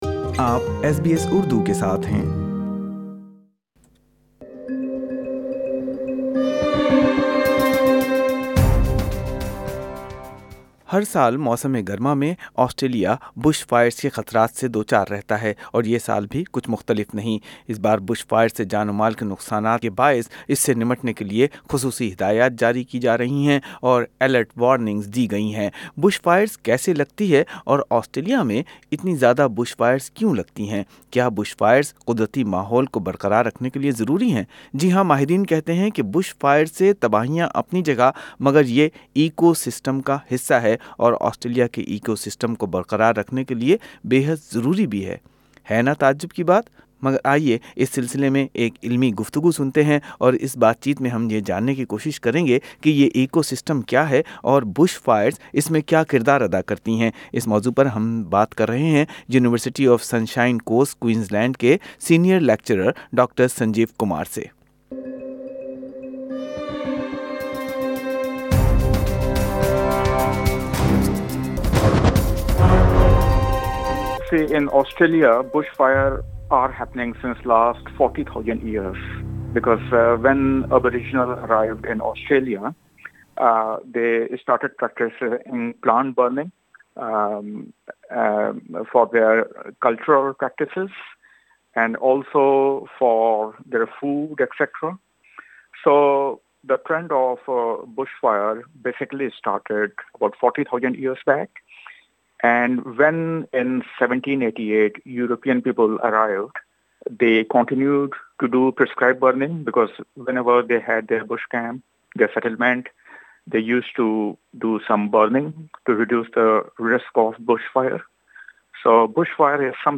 کیا بش فائیرش جنگلاتی ماحول کے نظام کی بقا کے لئے ضروری ہے؟ سنئیے اس موضوع کے ماہر اکیڈمیک کیا کہتے ہیں۔